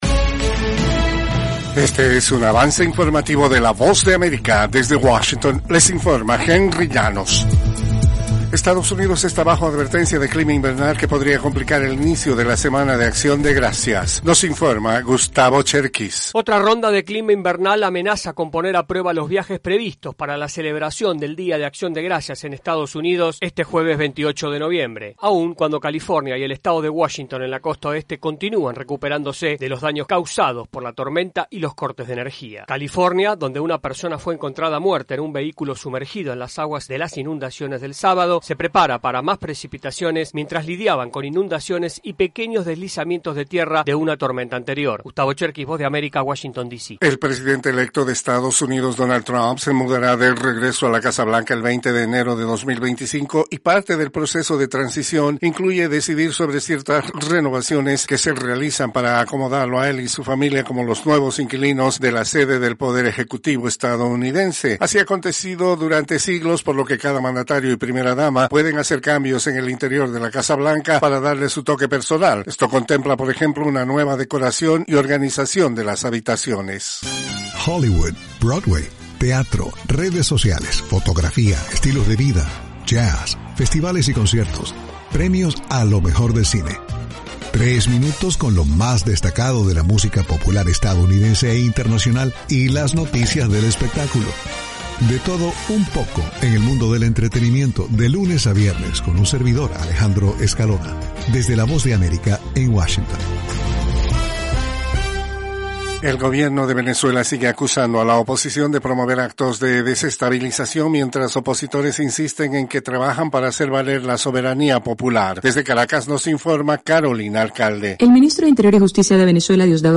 Cápsula informativa de tres minutos con el acontecer noticioso de Estados Unidos y el mundo.
Desde los estudios de la Voz de América en Washington